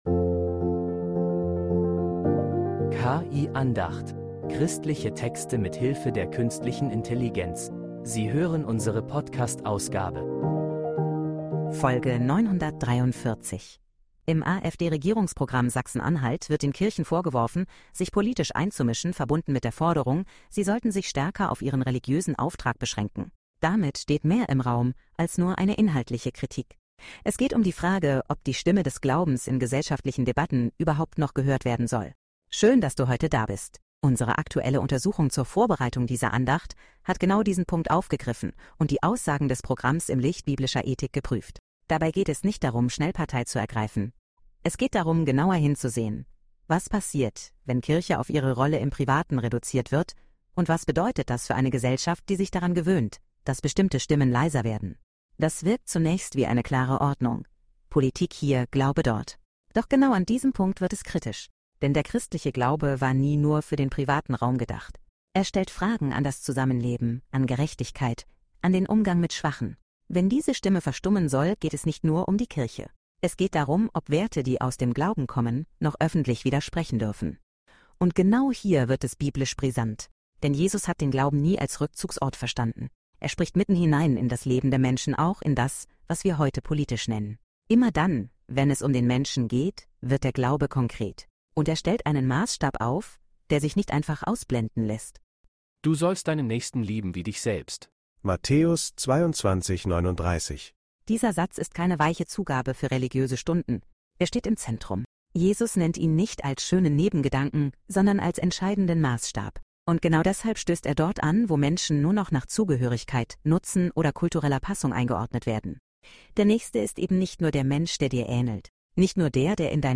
Diese Andacht stellt eine unbequeme, aber entscheidende Frage.